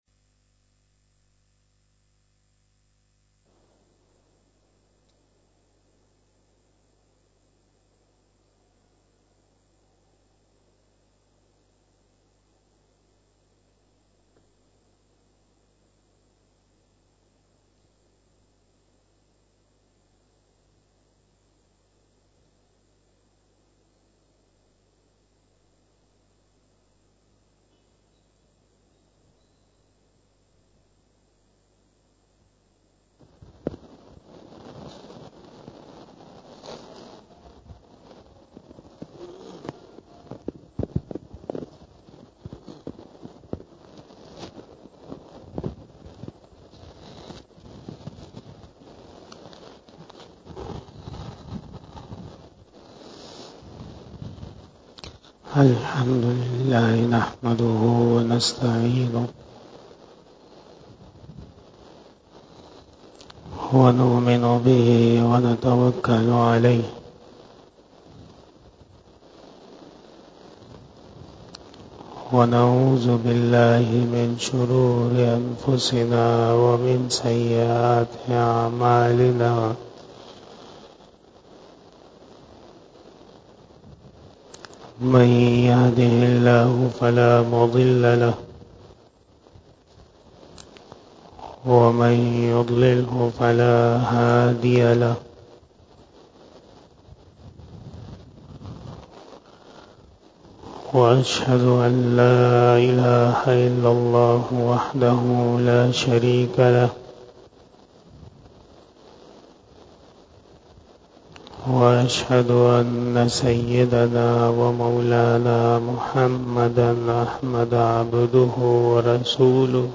28 BAYAN E JUMMAH 14 July 2023 (25 Zul Hajjah 1444HJ)
بیان جمعۃ المبارک 14 جولائی 2023 بمطابق 25 ذی الحجہ 1443ھ